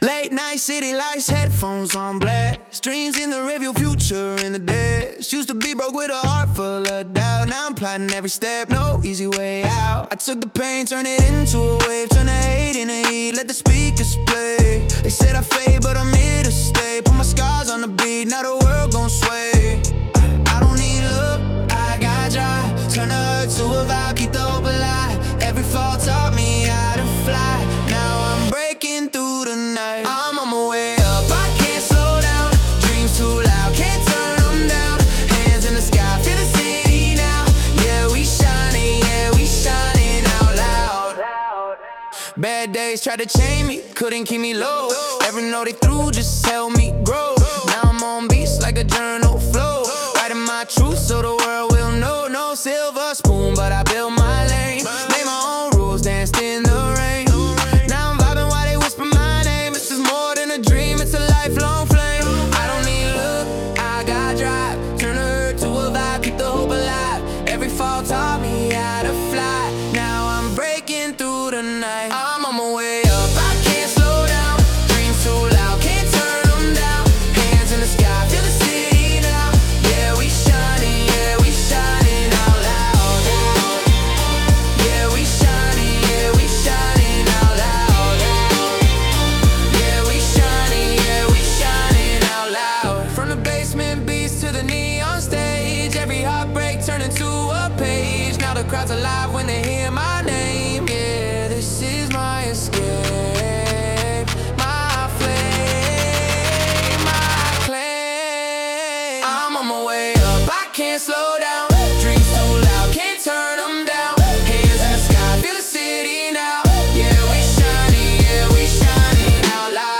Genre: Pop Mood: Motivational